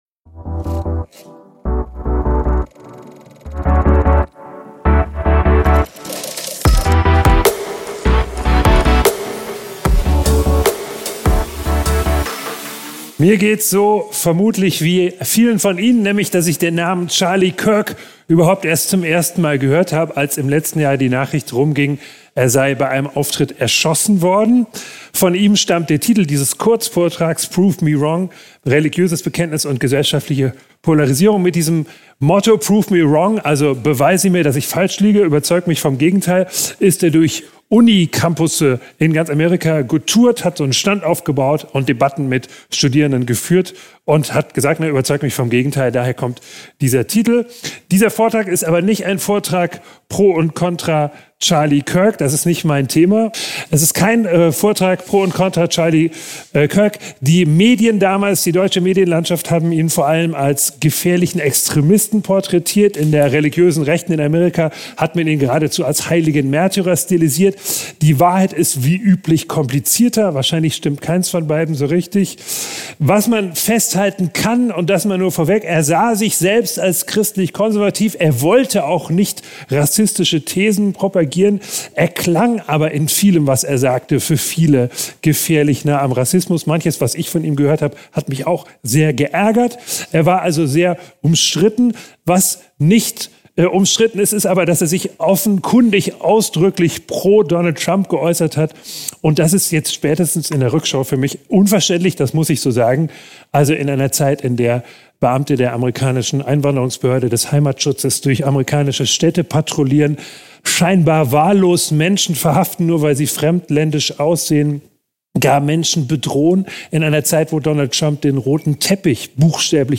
Wie viel Streit verträgt eine offene Gesellschaft – und was passiert, wenn religiöse Überzeugungen auf eine zunehmend polarisierte Öffentlichkeit treffen? Dieser Vortrag ist auf unserer diesjährigen Begründet-Glauben-Konferenz in Heimsheim entstanden!